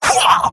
Robot-filtered lines from MvM. This is an audio clip from the game Team Fortress 2 .
Scout_mvm_beingshotinvincible15.mp3